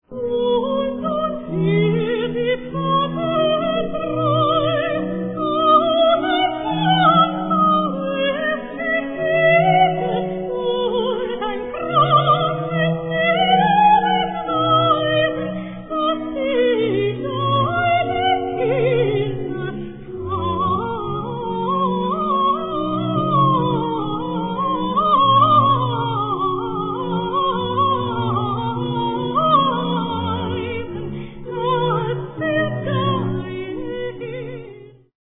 Three virtuosic soprano solo cantatas
Performed on period instruments.
3. Aria: